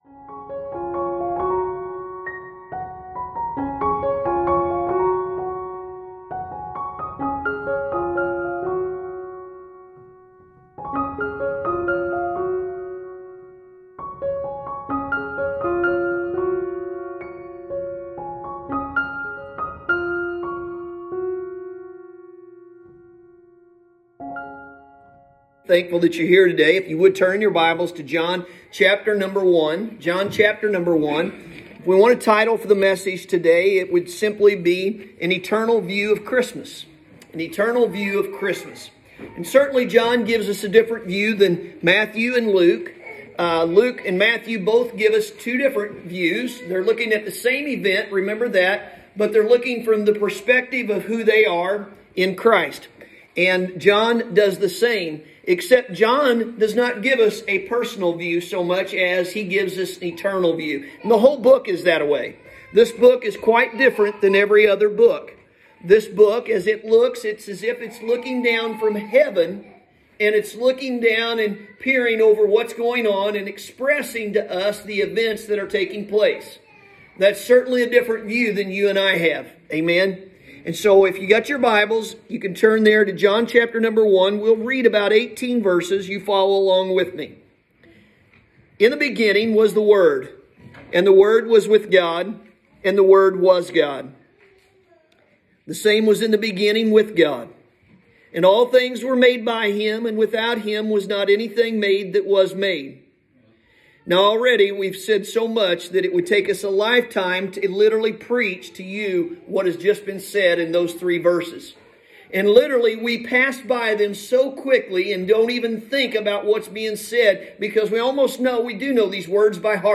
Sunday Morning – December 20th, 2020